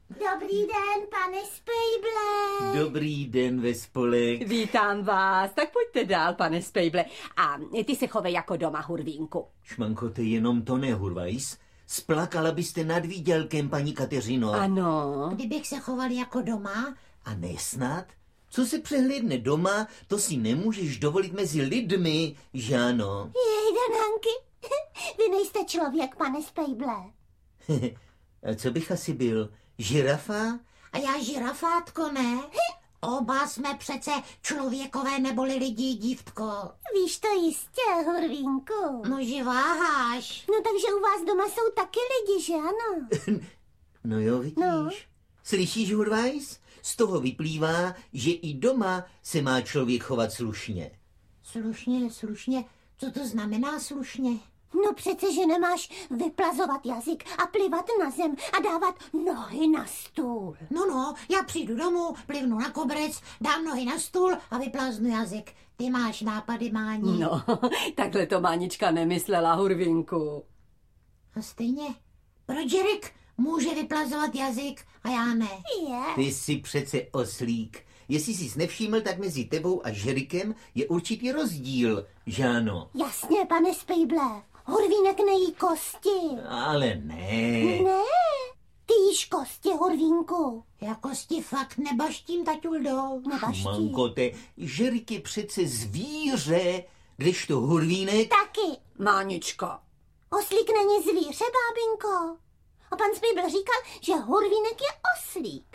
Hurvínkova abeceda slušného chování audiokniha
Audio kniha
• InterpretHelena Štáchová, Miloš Kirschner